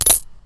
coin_silexaxe
coin_wAClUN7.mp3